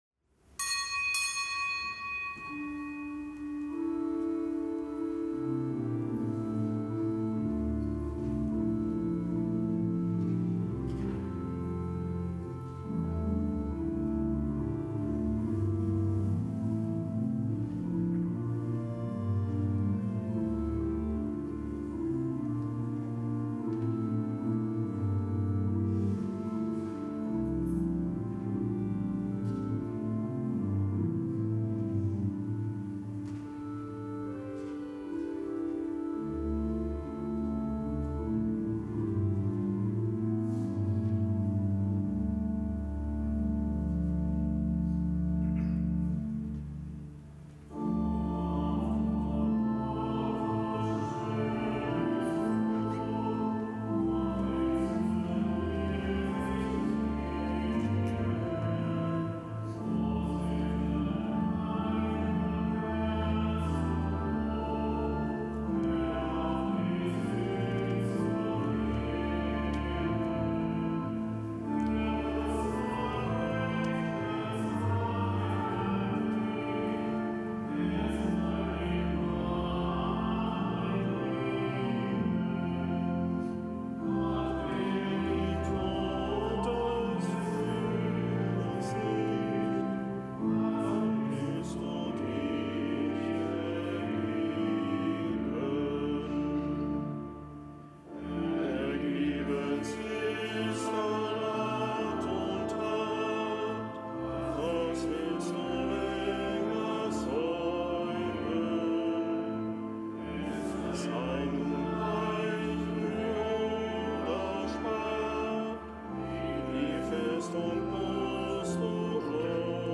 Kapitelsmesse aus dem Kölner Dom am Montag der dritten Fastenwoche. Zelebrant: Weihbischof Dominikus Schwaderlapp.